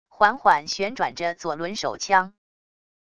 缓缓旋转着左轮手枪wav音频